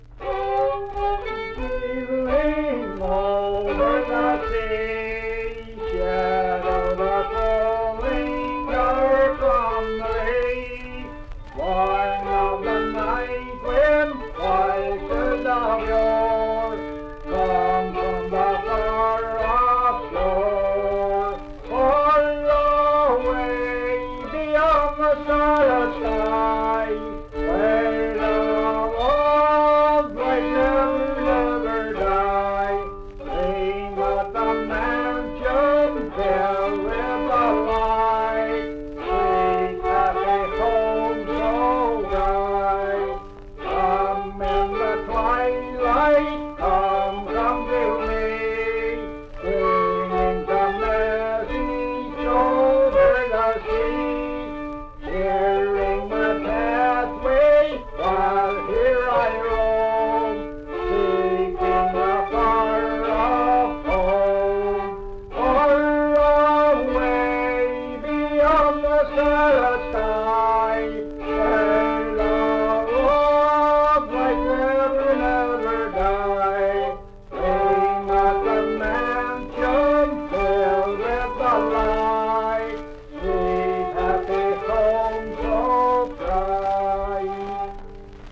Unaccompanied fiddle music
in Beverly, WV.
Instrumental Music
Voice (sung), Fiddle